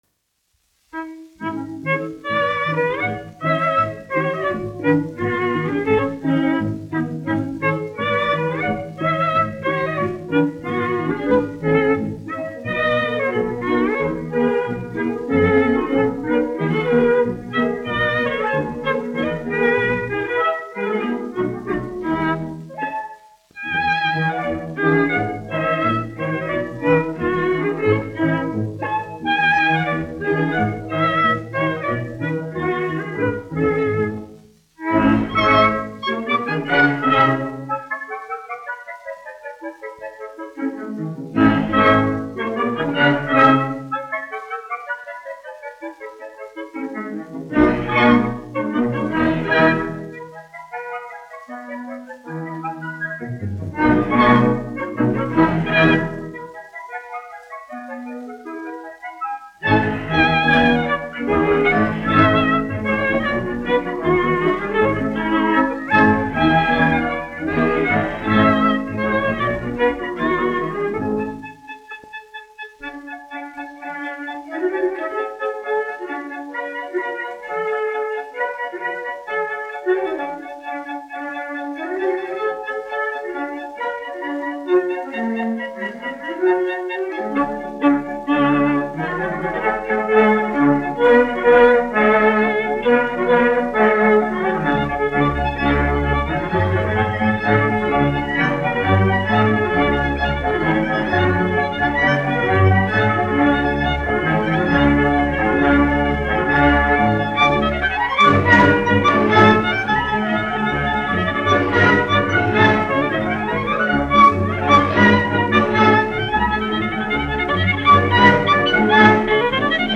Vadesco (salona orķestris), izpildītājs
1 skpl. : analogs, 78 apgr/min, mono ; 25 cm
Baleti--Fragmenti
Latvijas vēsturiskie šellaka skaņuplašu ieraksti (Kolekcija)